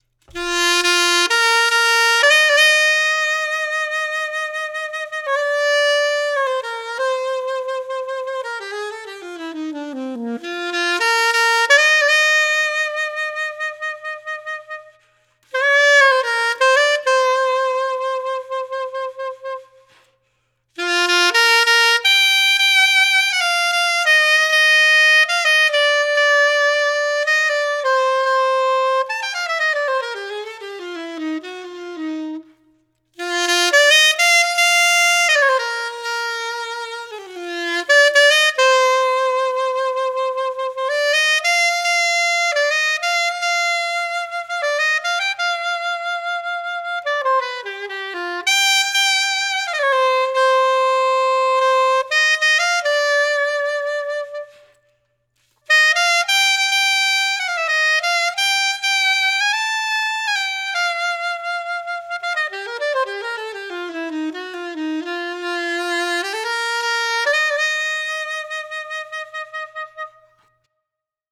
with reverb added.
Reverb Added